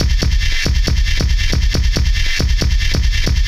METAL FIRE-L.wav